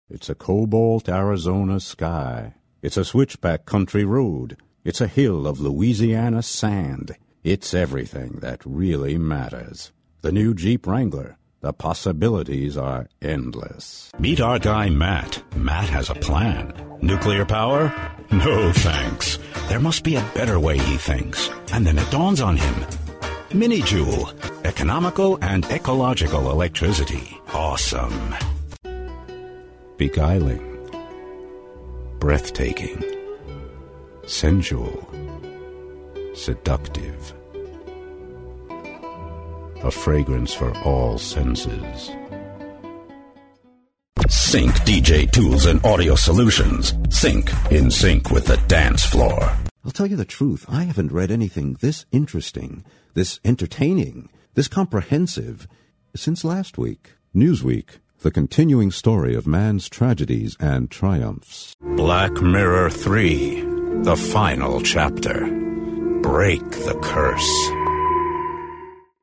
Native English Speaker; bass voice; technical, medical or documentary narration; excellent actor; short-notice access to studios
mid-atlantic
Sprechprobe: Werbung (Muttersprache):